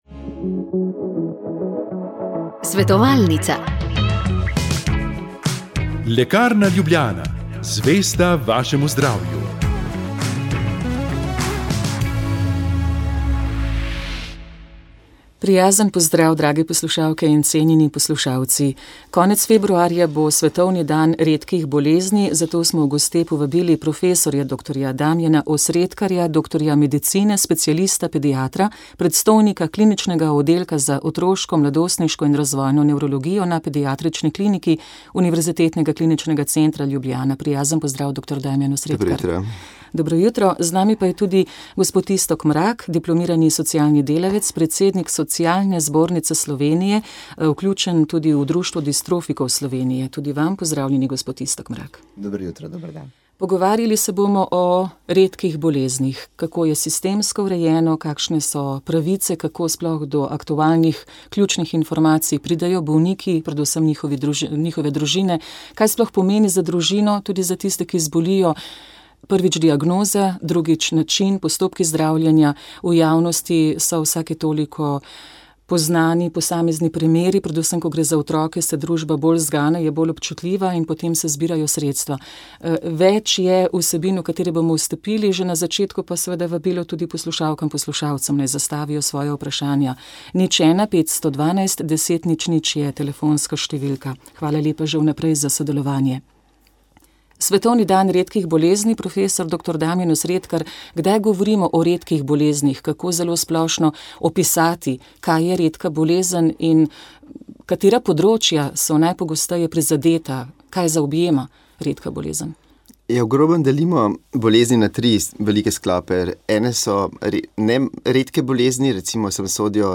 V oddaji smo odprli telefon za zbiranje spominov na začetke Radia Ognjišče. Poslušalci ste nam zaupali, kdaj ste prvič slišali naš program in kdo vas je nanj opozoril.